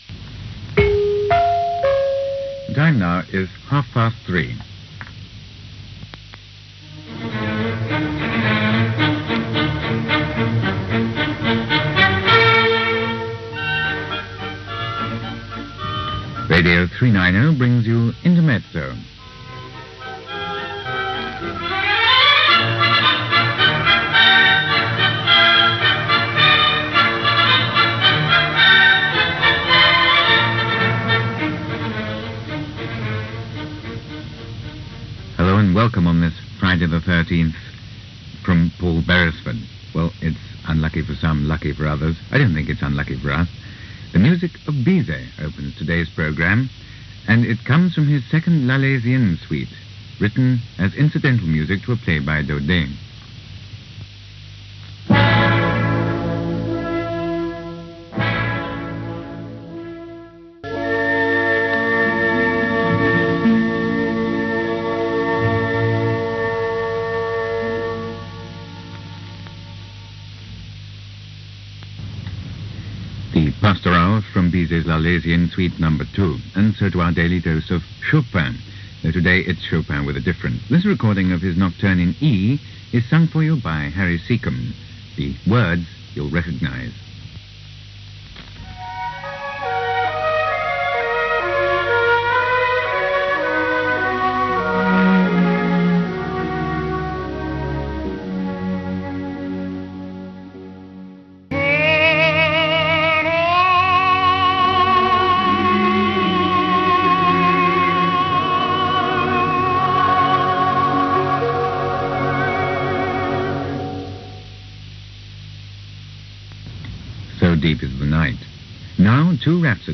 presenting Intermezzo and Masters Of The Organ on Radio 390
This is an edited version of a longer recording made available by The Offshore Radio Archive (duration 4 minutes 27 seconds)